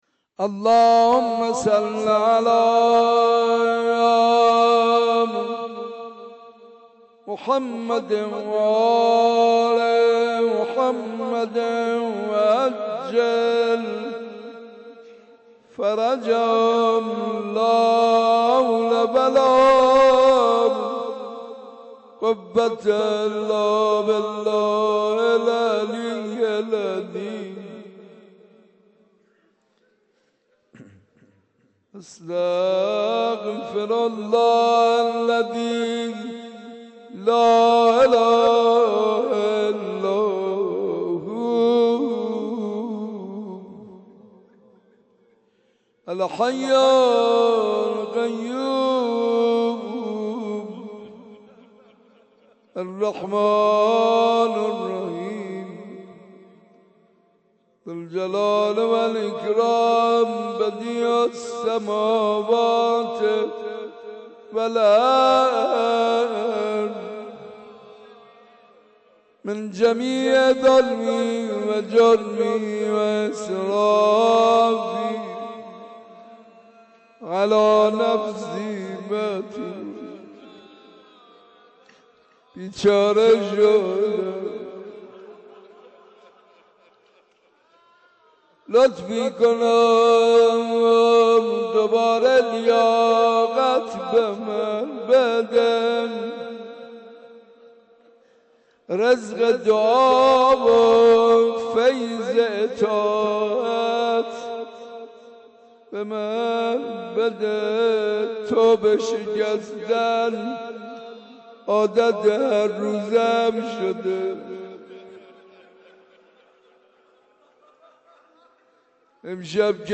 کد خبر : ۴۸۵۵۶ عقیق:صوت این مراسم ا بشنوید: دعای کمیل- حاج منصور ارضی لینک کپی شد گزارش خطا پسندها 0 اشتراک گذاری فیسبوک سروش واتس‌اپ لینکدین توییتر تلگرام اشتراک گذاری فیسبوک سروش واتس‌اپ لینکدین توییتر تلگرام